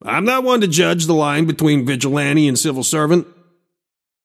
Shopkeeper voice line - I’m not one to judge the line between vigilante and civil servant.